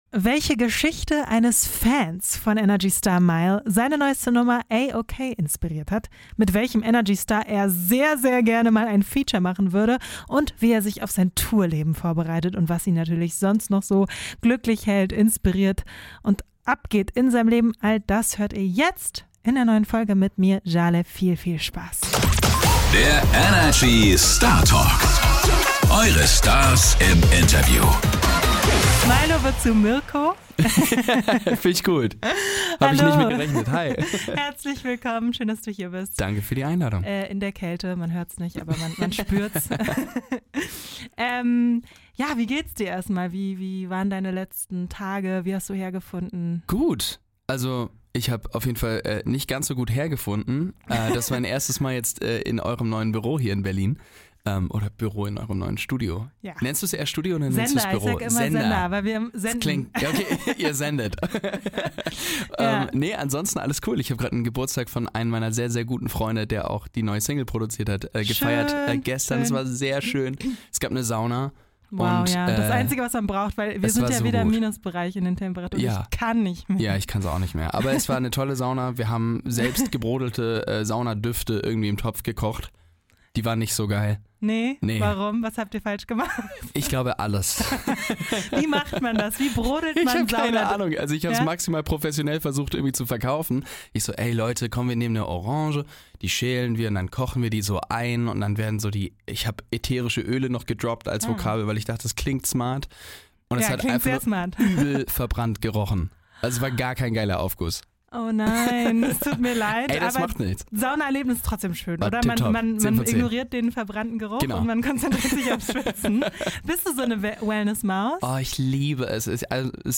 Ein lockeres, ehrliches Gespräch über neue Musik, große Pläne und das Leben zwischen Studio und Stage.